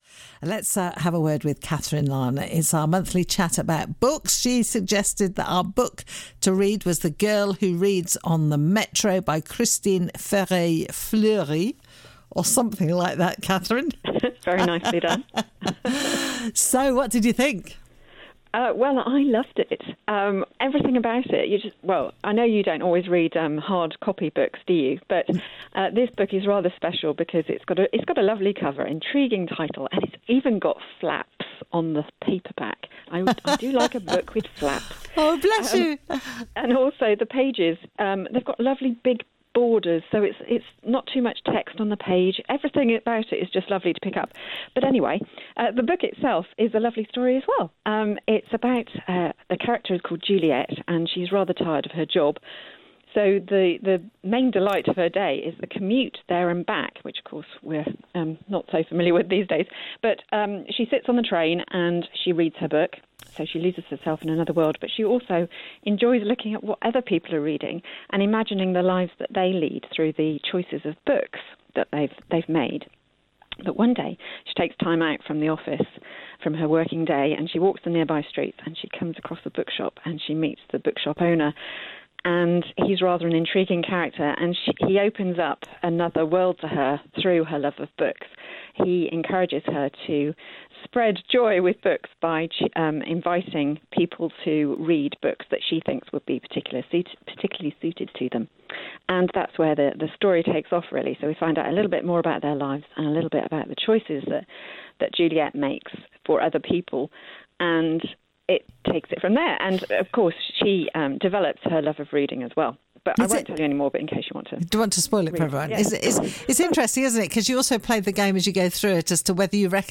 discussion